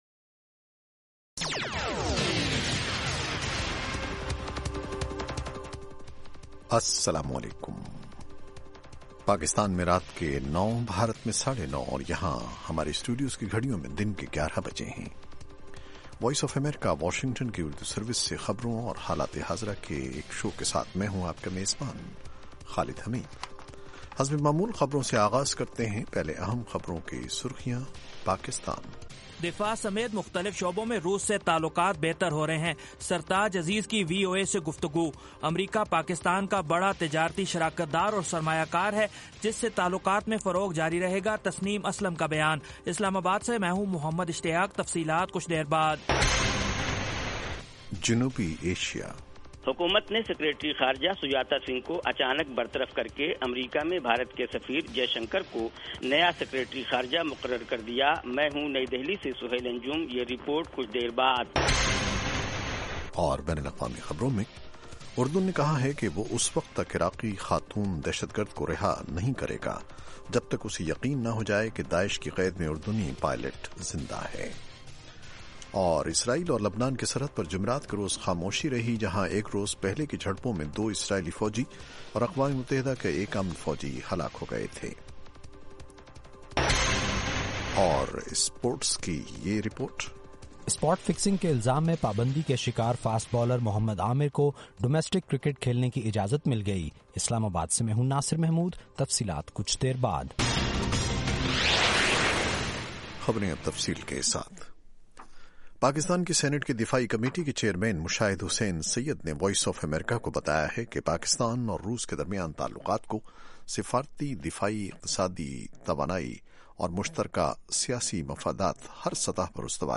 اس کے علاوہ انٹرویو، صحت، ادب و فن، کھیل، سائنس اور ٹیکنالوجی اور دوسرے موضوعات کا احاطہ۔